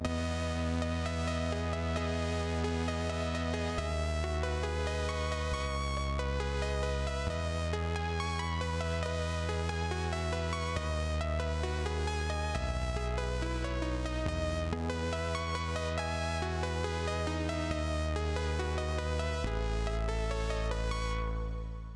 i dont feel like going through all my presets now but here’s six i just did. some are more similar just gritty fm brassy stuff, but i there is a nice quality to the synth where you can get high notes that are clean woody almost digital dx fm sounding but organic while the low notes and chords are fuzzy and warm at the same time. I’ll try to find some of those later, but these are just some i was able to record quickly just now. sorry about the volume differences, i forgot to normalize a couple. also excuse my playing, i suck